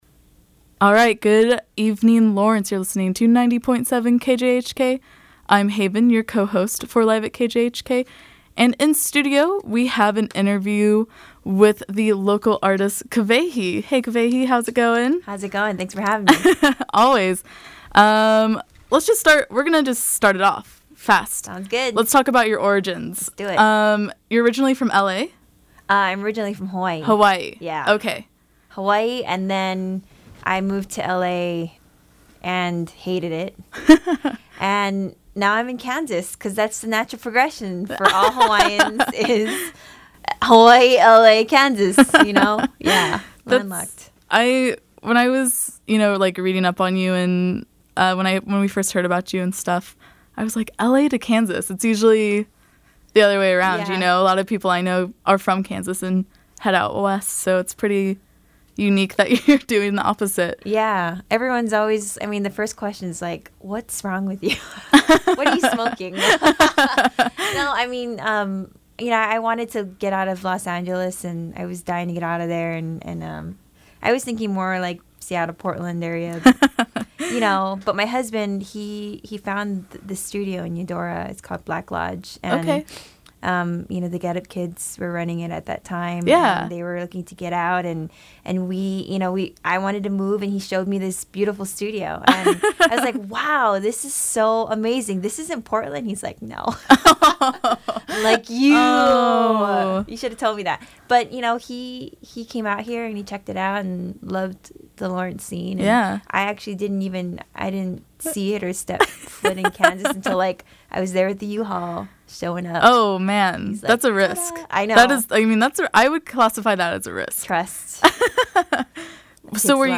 You can listen to the full interview right here (see audio link below), and catch it on the air during the Local Music Block from 6-7 p.m. Tuesday, Nov. 4; during Ad Astra Radio from 7-8 p.m. Wednesday, Nov. 5; and during Live @ KJHK from 7-8 p.m. Thursday, Nov. 6.